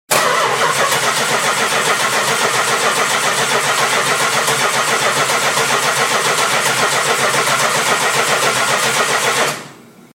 The resultant starter looks just like every other '74-'89 large-frame Chrysler OSGR starter, but doesn't sound like either the small-frame or the large-frame starter.
And you can listen to it cranking a 383 in a '66 Dodge,
cold and
3-Series_1-shunt_largeframe_Cold_Crank.mp3